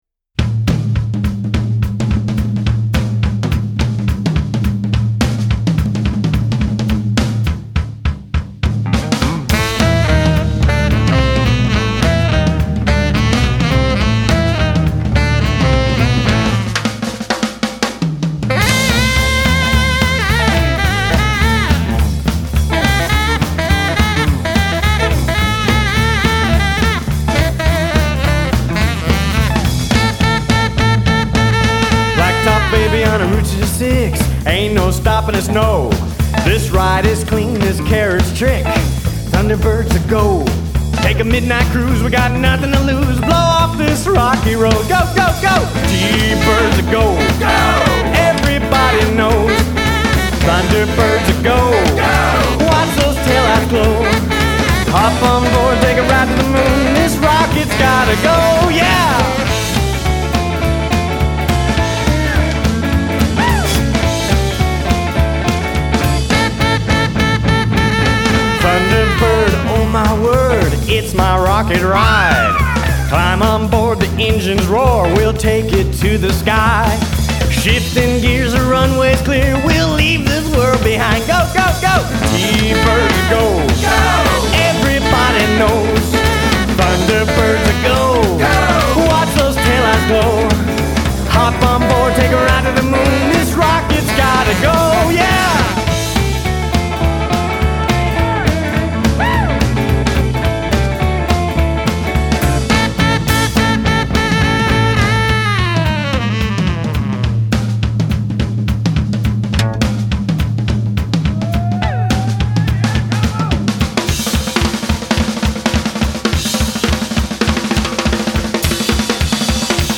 Vocals & Guitar
Bass & Vocals
Lead Guitar
Drums
Sax